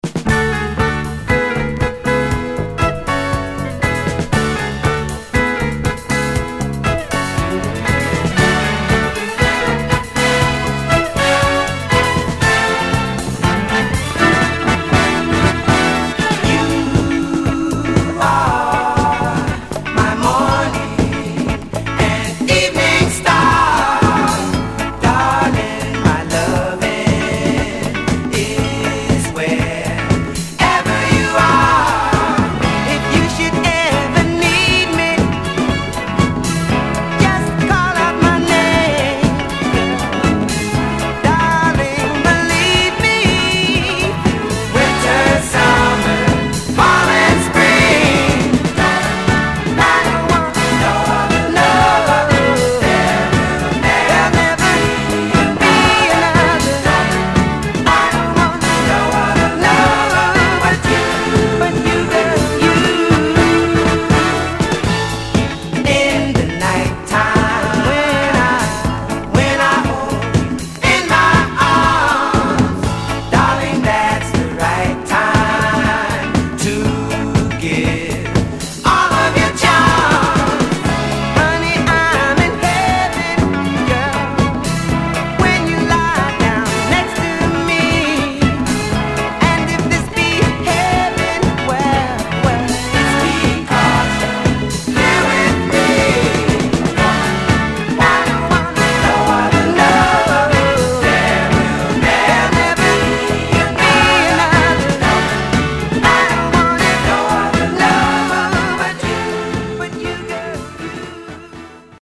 ・ 45's SOUL / FUNK / DISCO / JAZZ / ROCK